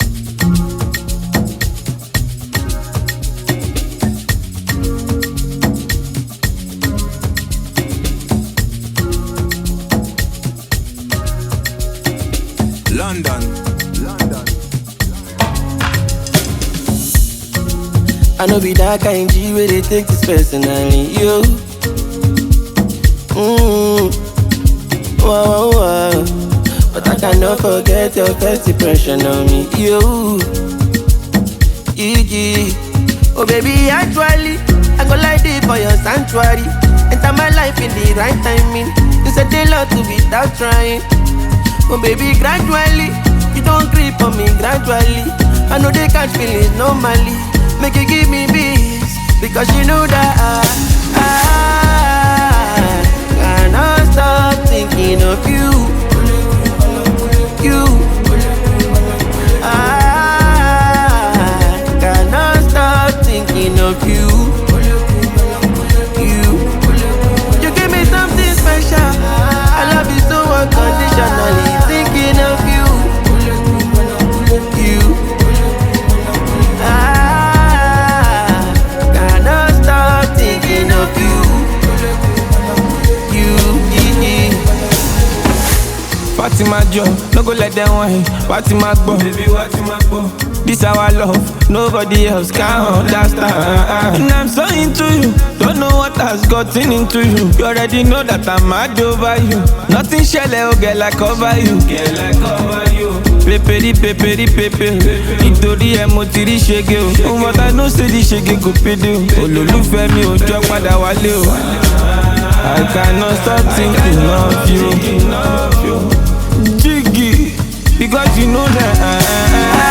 smooth vocals
infectious energy